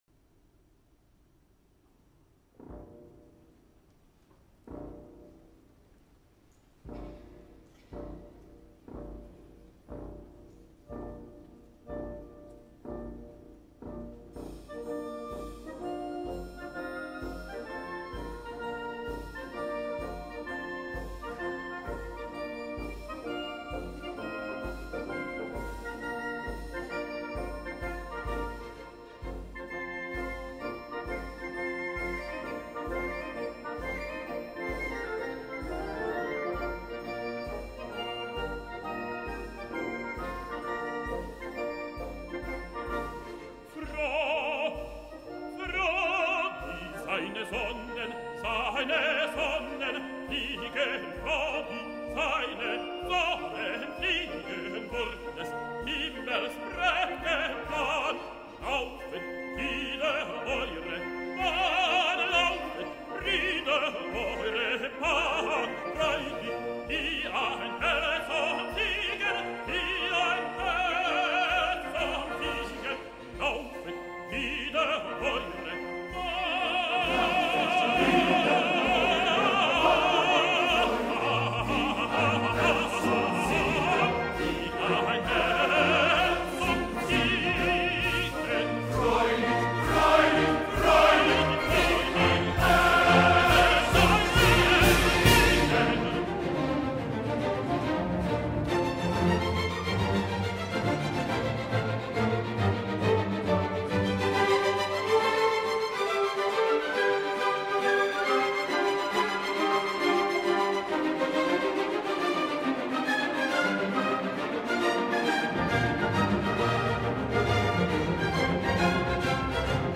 Classical Songs
Beethoven_-Symphony-No.-9-in-D-minor-Choral-BBC-Proms-2015.mp3